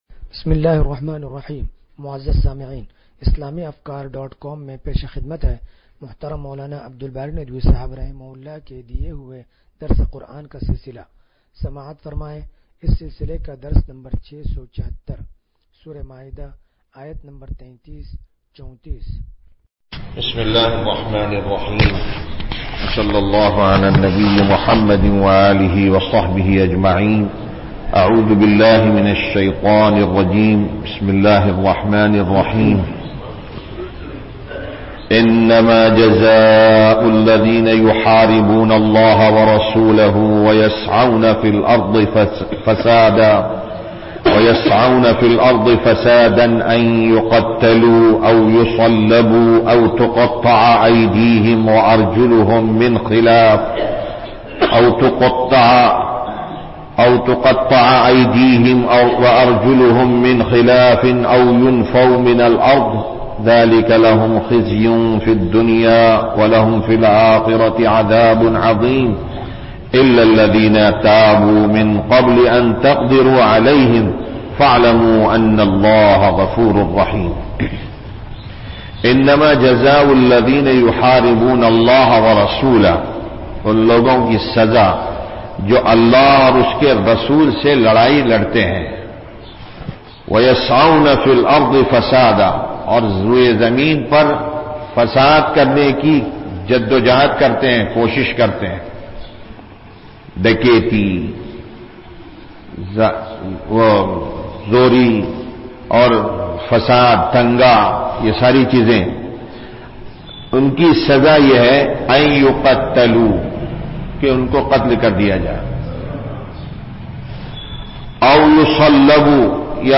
درس قرآن نمبر 0676
درس-قرآن-نمبر-0676.mp3